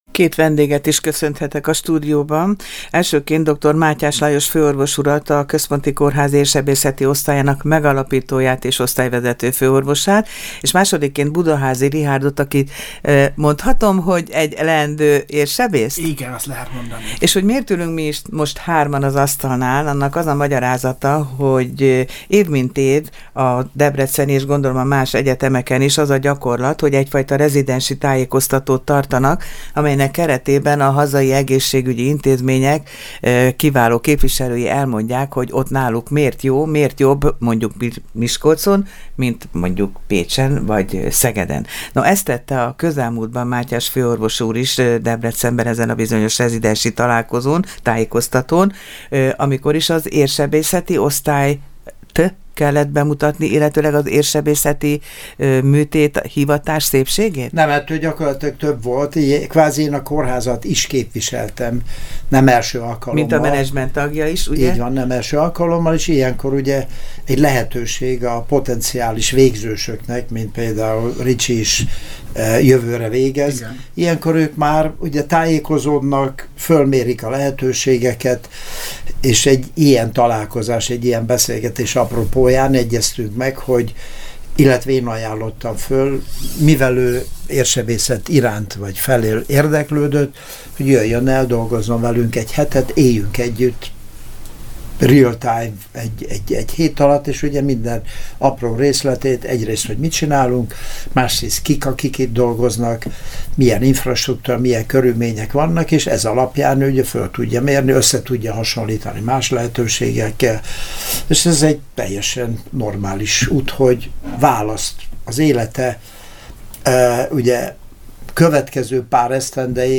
Vannak olyan tájékoztatók is, amelyek során az egészségügyi intézmények vezetői, képviselői látogatnak el az orvosképző egyetemekre és ismertetik azokat a lehetőségeket, amelyek kórházukat jellemzik, és amelyekről egyébként személyesen is tapasztalatokat szerezhetnek a leendő orvosok. Ezt példázza az a beszélgetés is